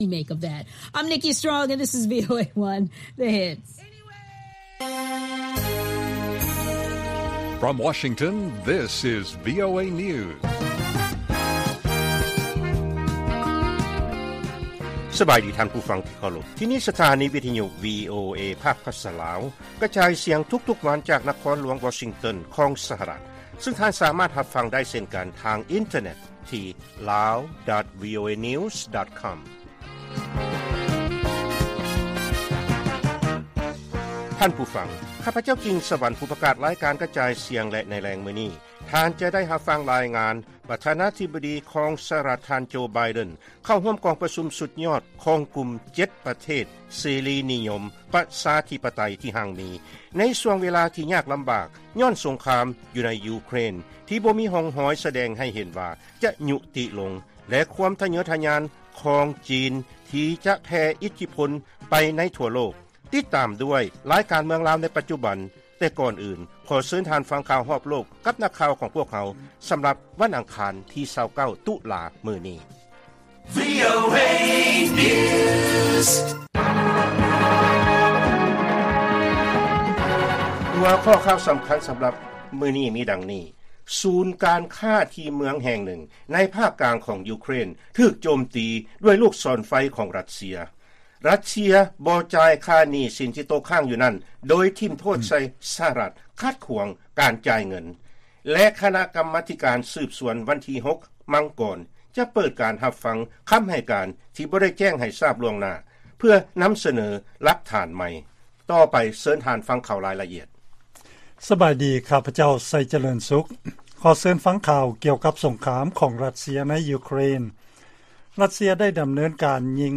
ລາຍການກະຈາຍສຽງຂອງວີໂອເອ ລາວ: ສູນການຄ້າ ທີ່ເມືອງແຫ່ງນຶ່ງ ໃນພາກກາງຂອງຢູເຄຣນ ຖືກໂຈມຕີໂດຍລູກສອນໄຟ ຂອງຣັດເຊຍ